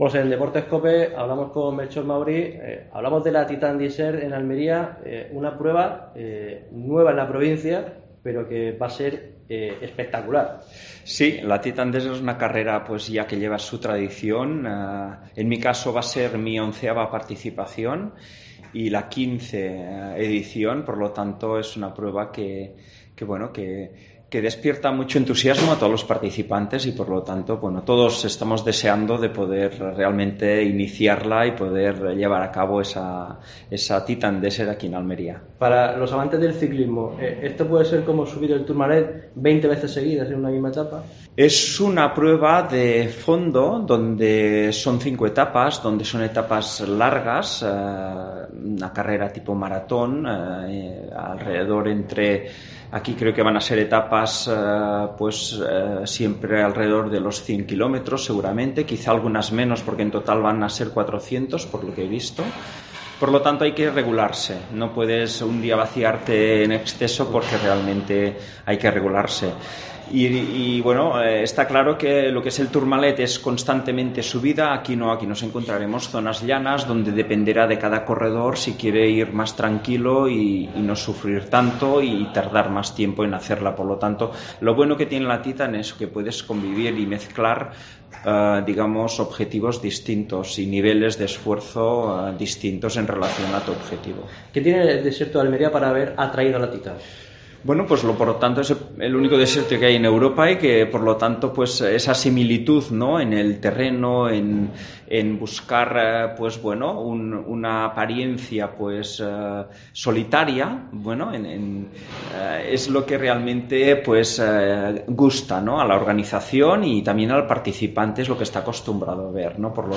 Así lo ha reconocido en una entrevista con Deportes COPE Almería, que podéis escuchar en el audio de esta noticia.